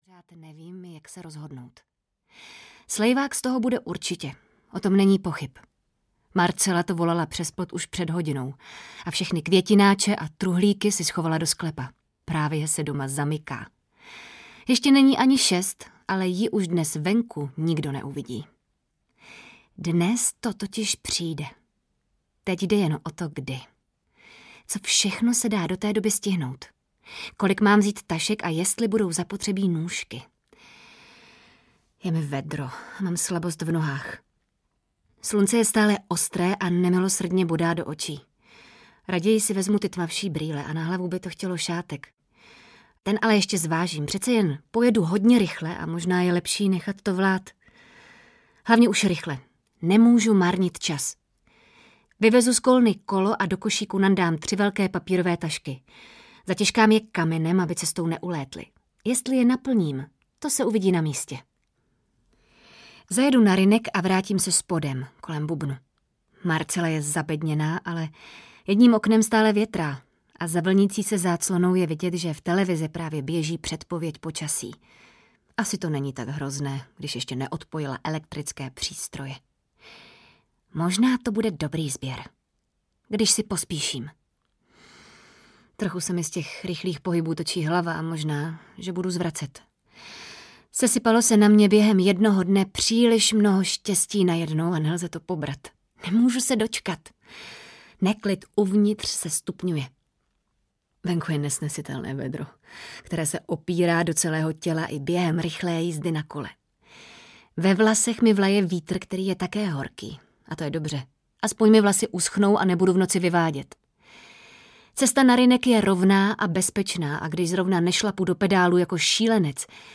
Do tmy audiokniha
Ukázka z knihy
• InterpretAnita Krausová